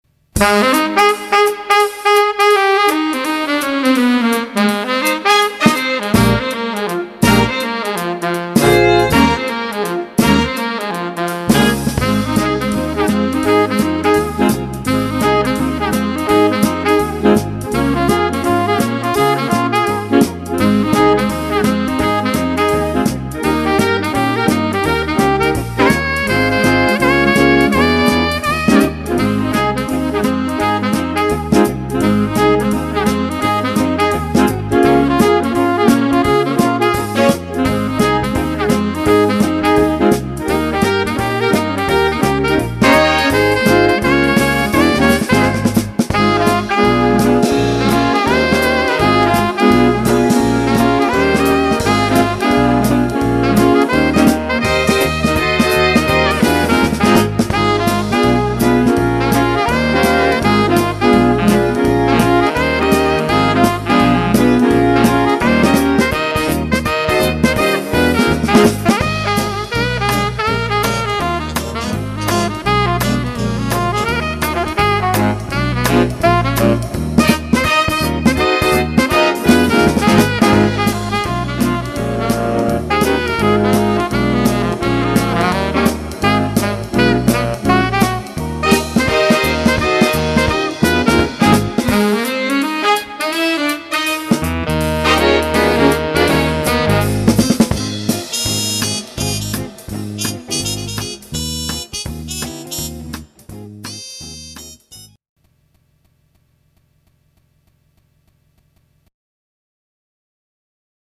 Hörprobe 4 (Saxophon) :